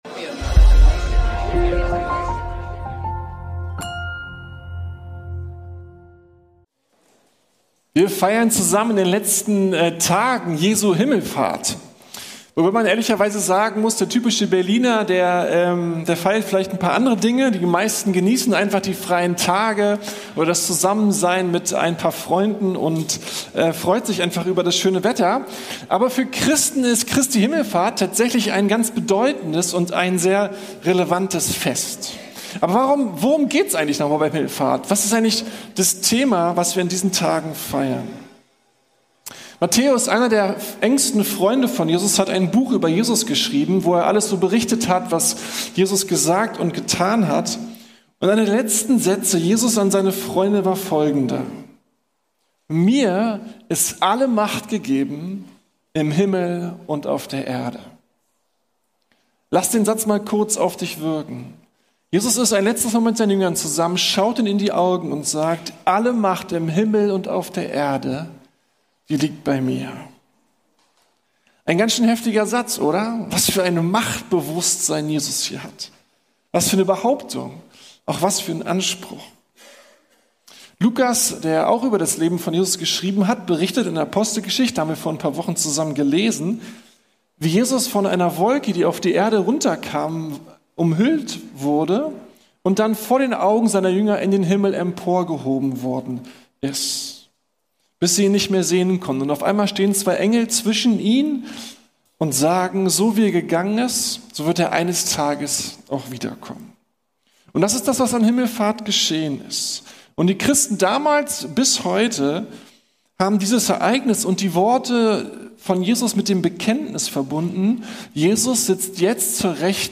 Gottes Mission - Jesu Herrschaft ~ Predigten der LUKAS GEMEINDE Podcast